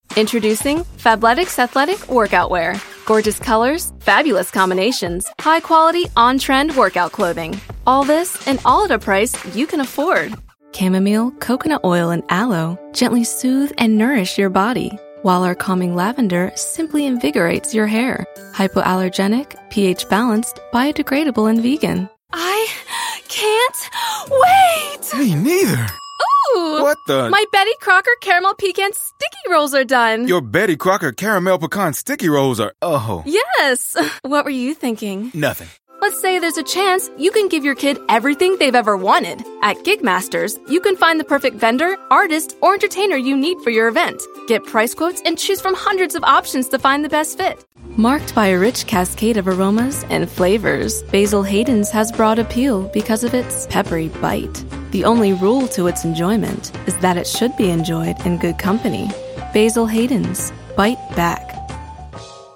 Teenager, Adult, Young Adult
commercial
authoritative
friendly
smooth
sophisticated
warm
well spoken